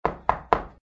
GUI_knock_4.ogg